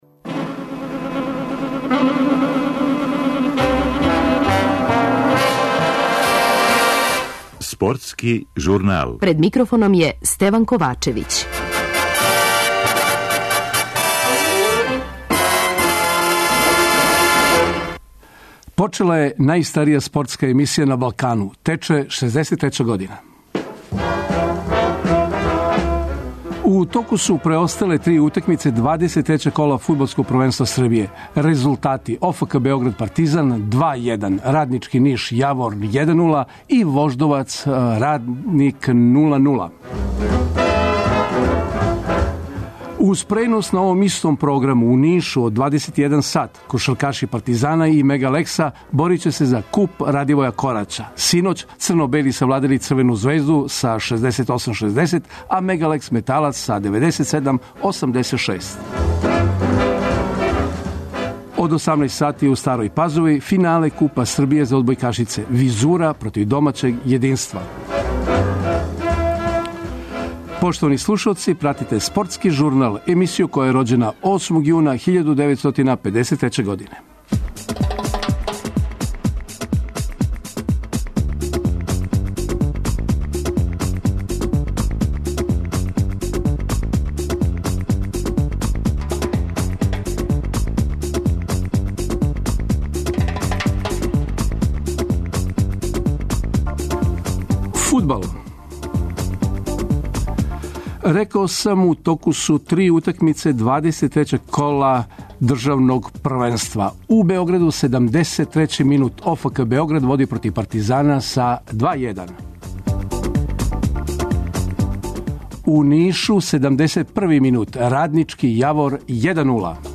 Наши репортери јавиће се из Ниша, где се дванаести пут одржава завршница Купa „Радивоја Кораћа".